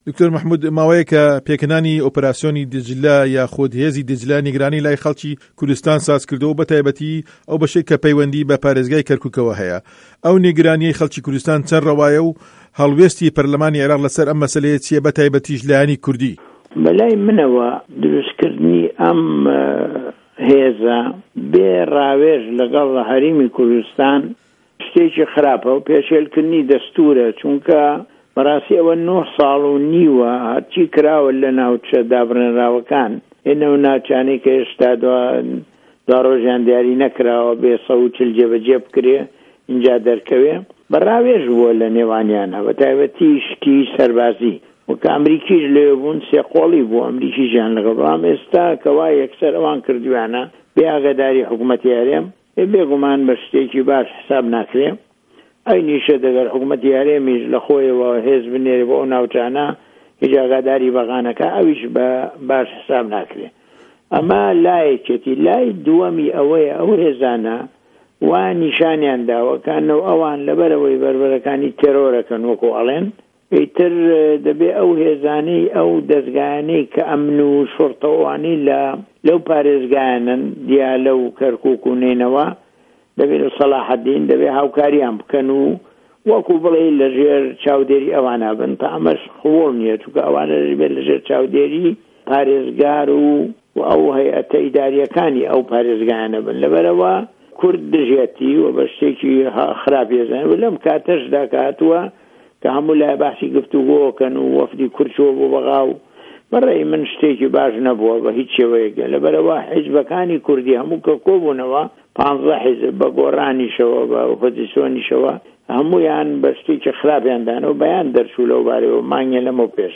وتووێژی دکتۆر مه‌حمود عوسمان